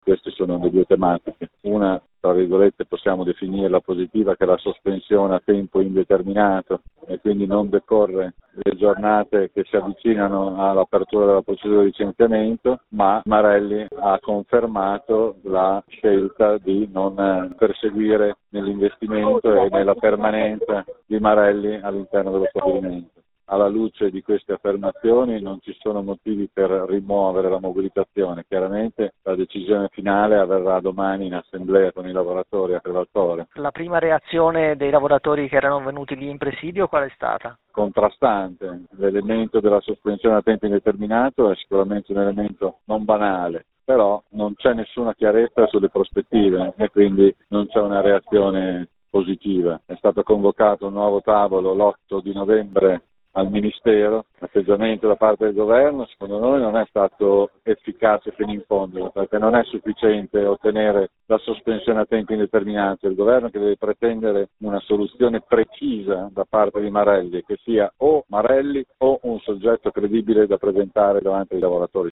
Lo abbiamo intervistato dopo l’incontro, mentre era coi lavoratori riuniti in presidio fuori dal ministero